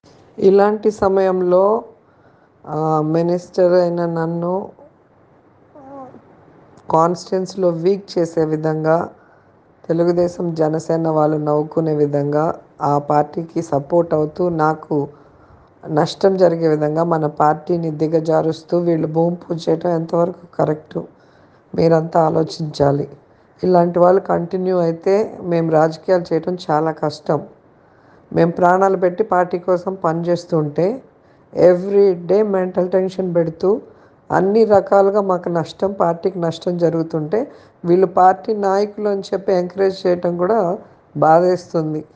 నగరి నియోజకవర్గంలో తనను బలహీన పరిచే కుట్ర జరుగుతోందని ఈ ఆడియో మెసేజ్‌లో ఆందోళన వ్యక్తం చేశారు.
మంత్రి రోజా విడుదల చేసిన ఆడియోను ఇక్కడ వినండి..